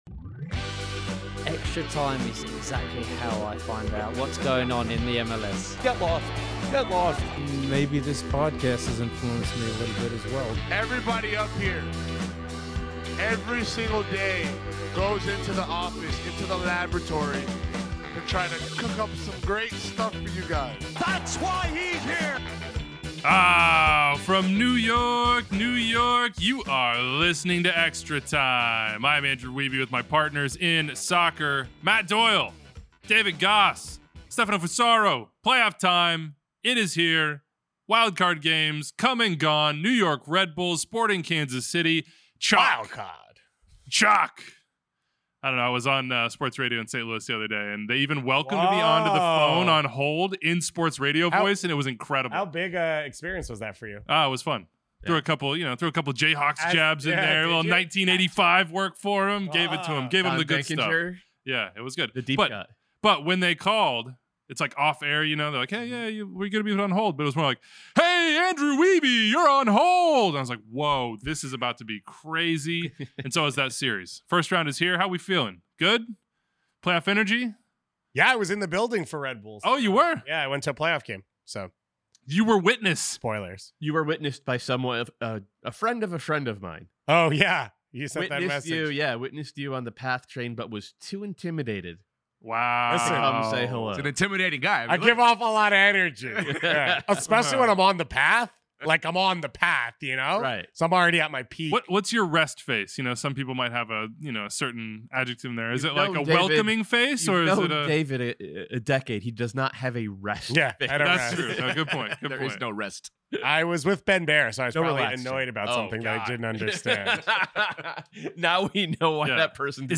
54:47 - INTERVIEW: Duncan McGuire on the art of goal scoring